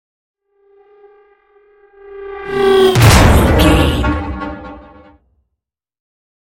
Sci fi whoosh to hit horror
Sound Effects
Atonal
dark
intense
tension
woosh to hit